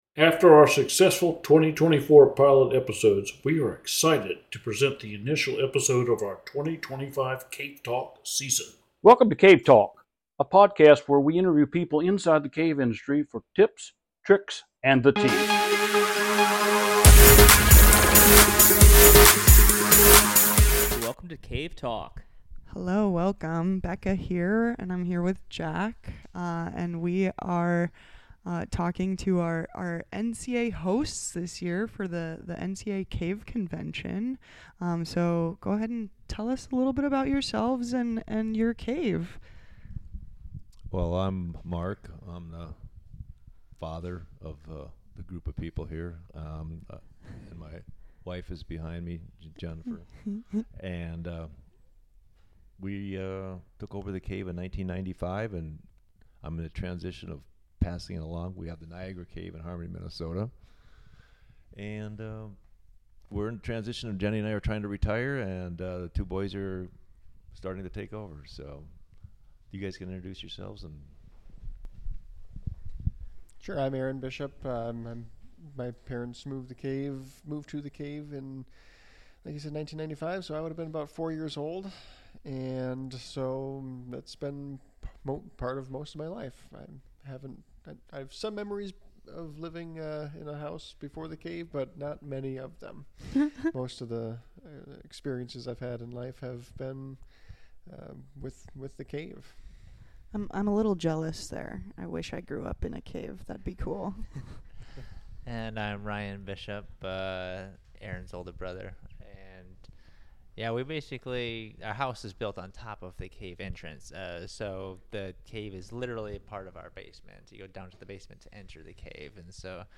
sit down at the National Cave Convention